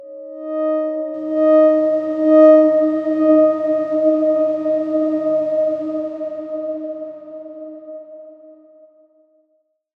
X_Darkswarm-D#4-pp.wav